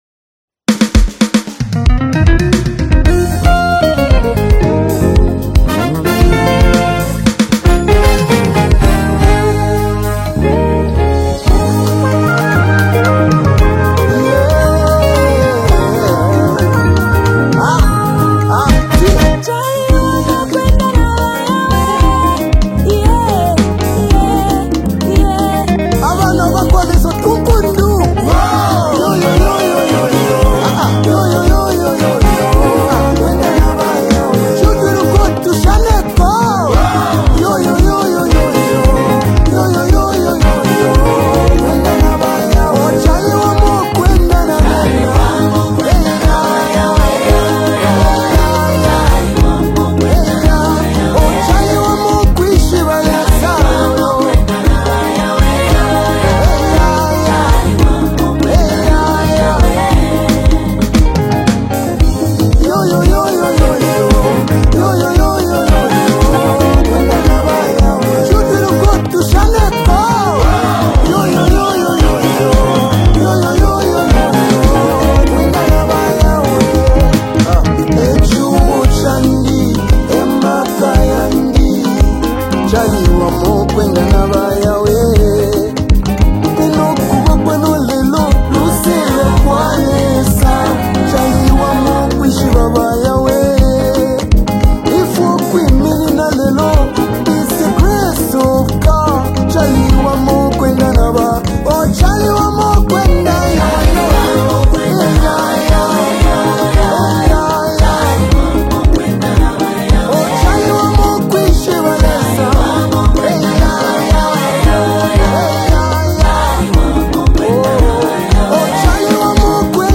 Gospel anthem
Produced with a vibrant yet reverent sound